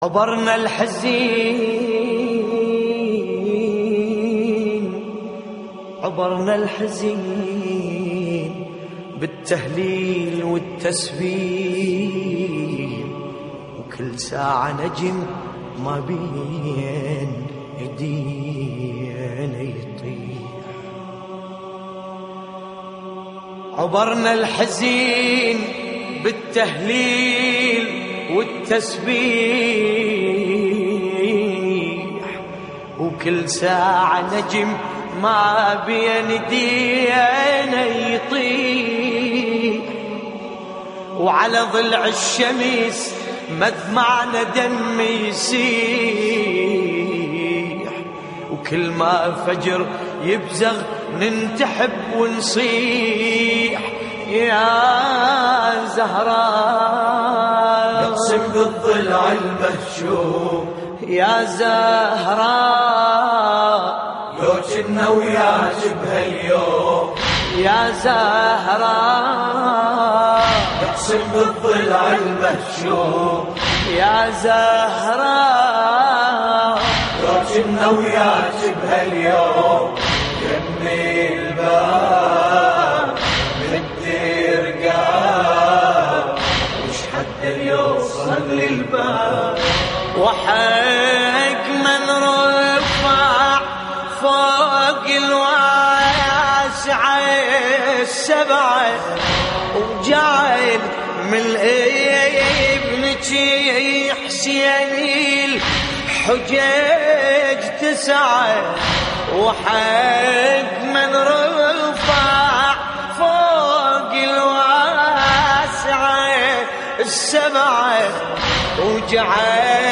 استديو